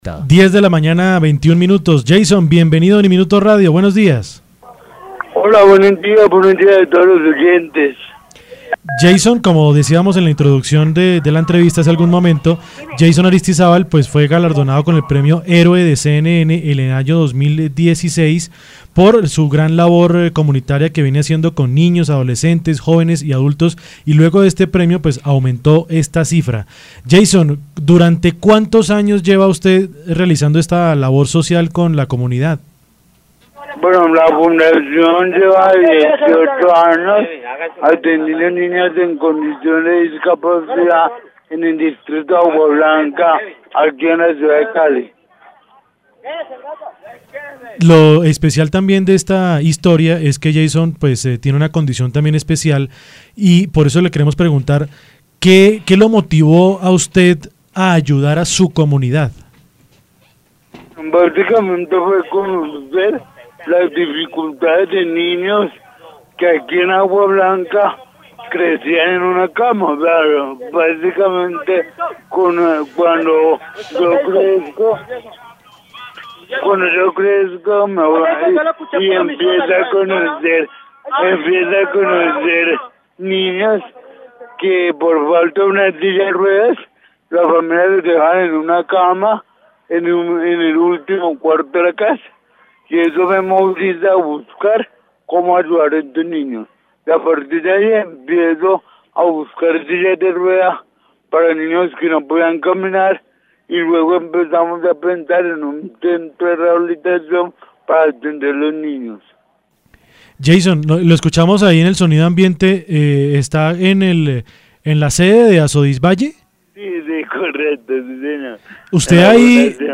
En diálogo con UNIMINUTO Radio